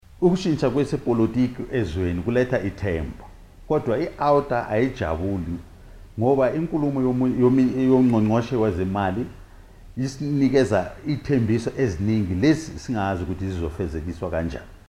Soundbite in